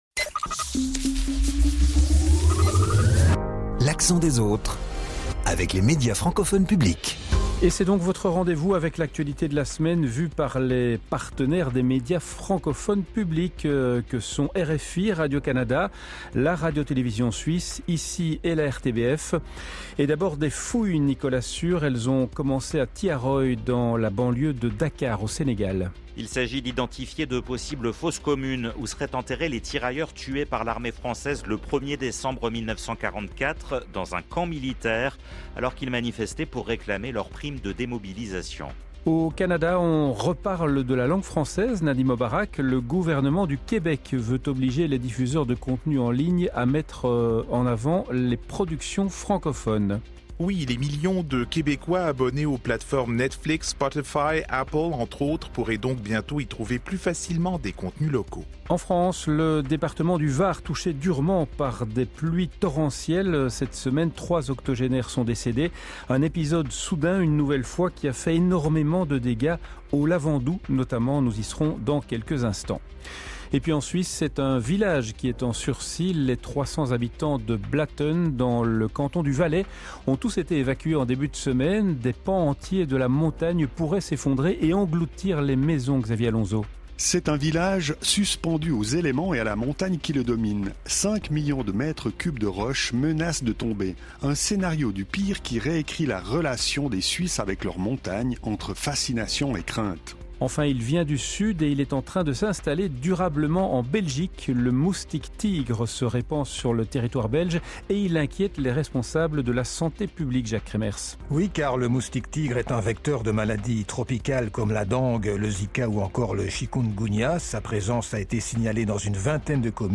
De l’Afrique à l’Amérique du Nord, en passant par l’Europe, l’Accent des autres propose chaque samedi un tour d’horizon de ce qui a fait l’actualité durant la semaine dans les différentes régions du monde francophone.